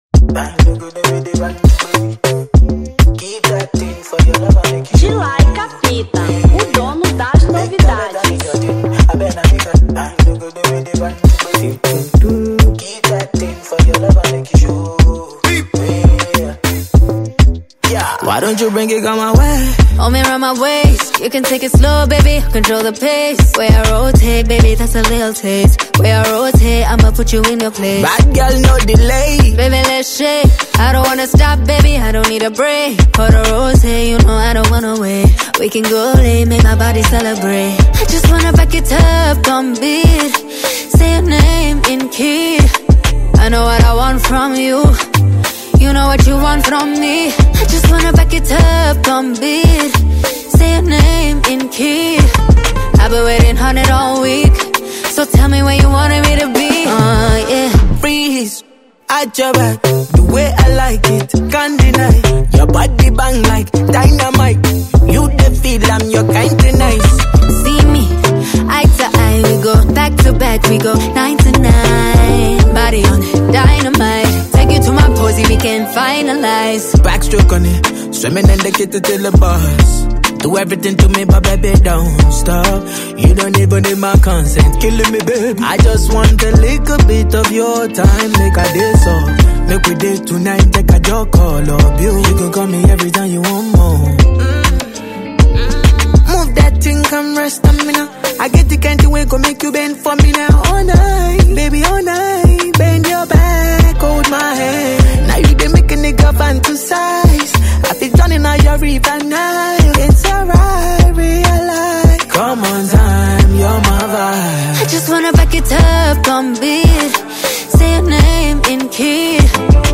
Afro Pop 2025